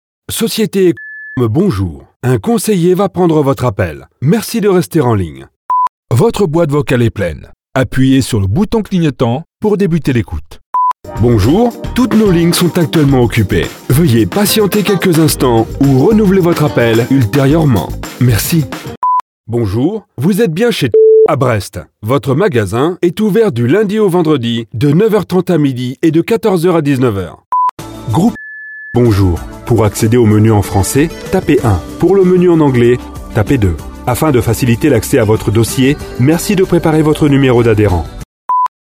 Profonde, Naturelle, Chaude, Douce, Commerciale, Polyvalente
Téléphonie